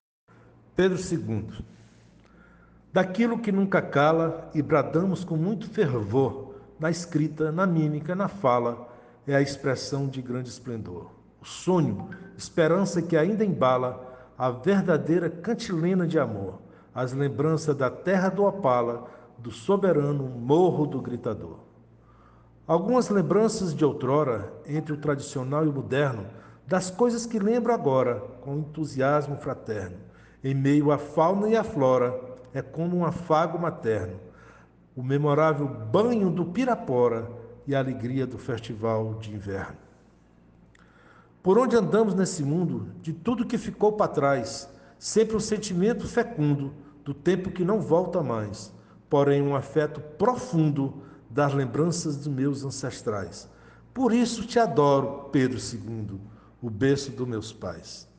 01:25:00   Verso Recitado